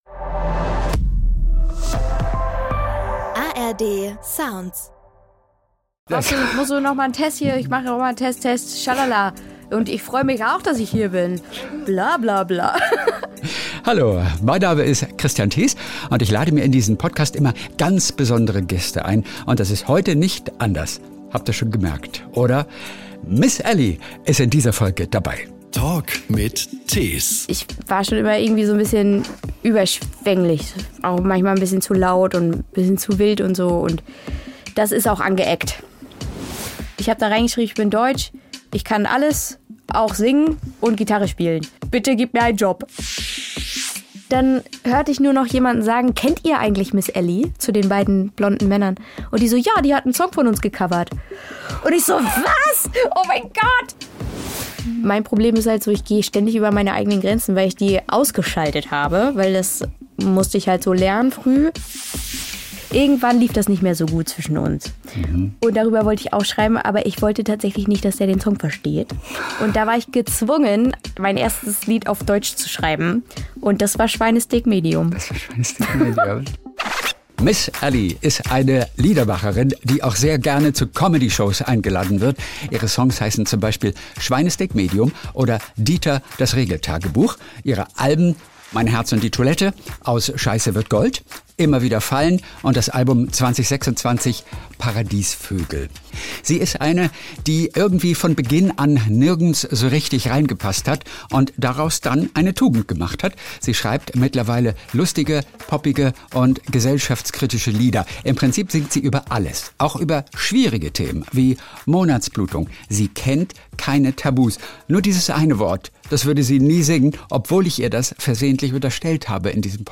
Es gibt viel zu lachen, aber auch nachdenkliche und traurige Momente in diesem wunderschönen Gespräch.